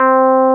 ElectricPiano_C4_22k.wav